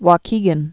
(wô-kēgən)